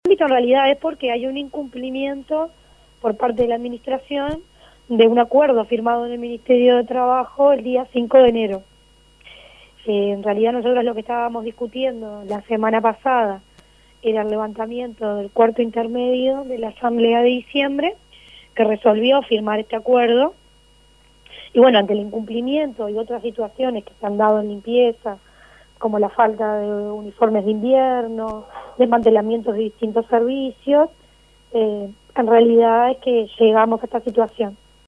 Un grupo de funcionarios municipales copó este mediodía el corredor frente al despacho del intendente Ricardo Ehrlich, en el Palacio Municipal. Portetaron contra "la privatización" del Hotel Casino Carrasco y de la Usina 5.